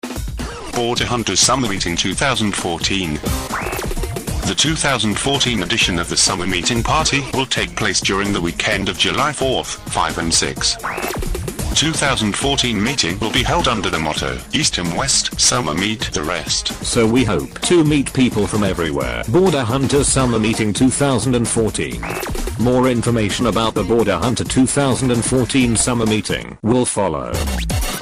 Hereby attached a “announcement jingle” for Summermeeting 2014!